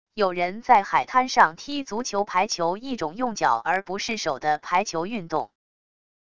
有人在海滩上踢足球排球――一种用脚而不是手的排球运动wav音频